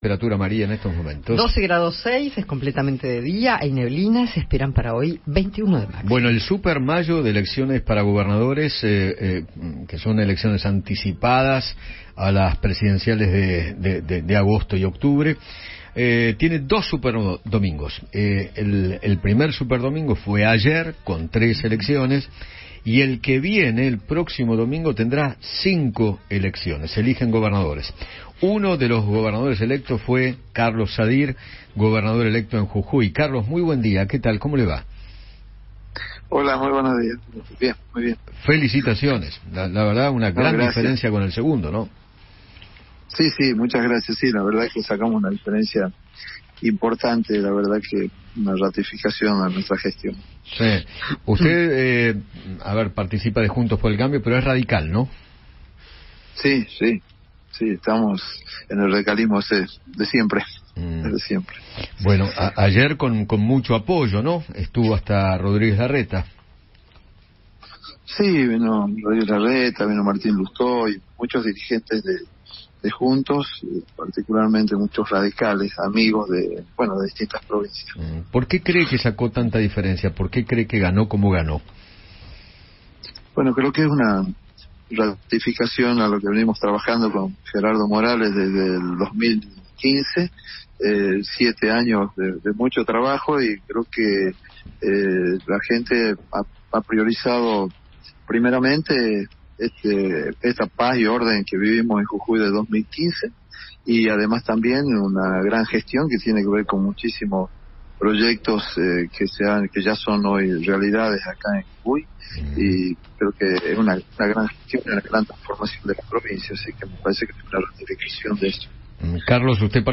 Carlos Sadir, flamante gobernador de Jujuy, conversó con Eduardo Feinmann sobre el resultado de las elecciones provinciales.